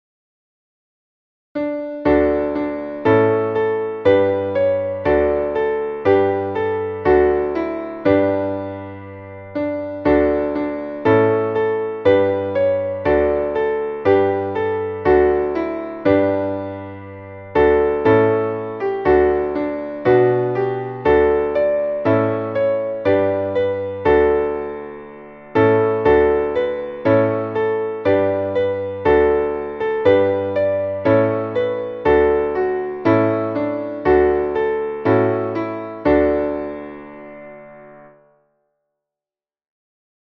Traditionelles Neujahrs-/ Winter-/ Frühlingslied